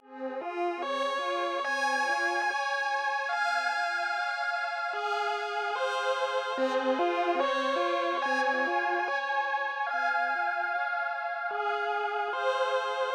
Feral_Pad.wav